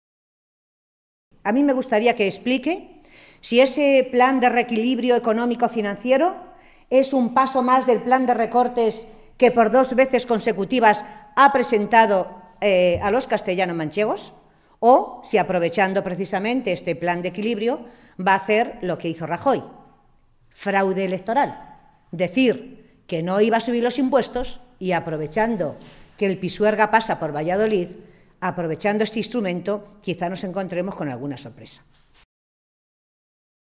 Matilde Valentín, portavoz en materia de Asuntos Sociales del Grupo Parlamentario Socialista
Cortes de audio de la rueda de prensa